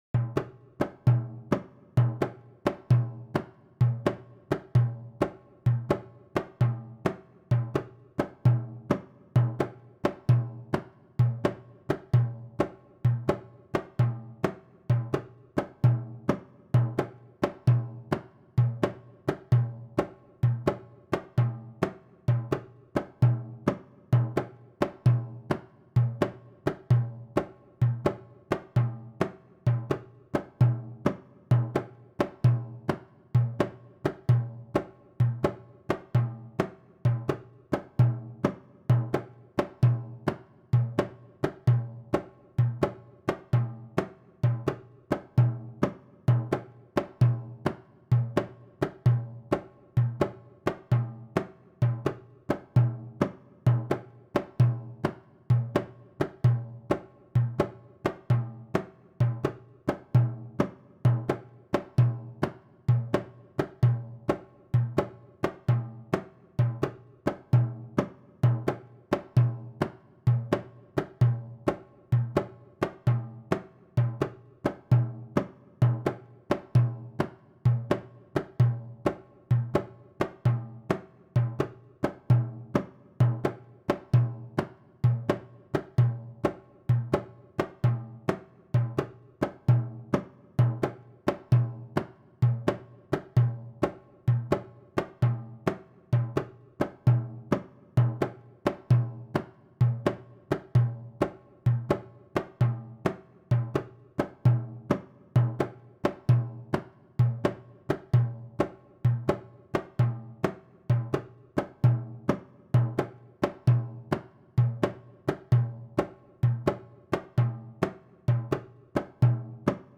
Bases gratuitas para brincar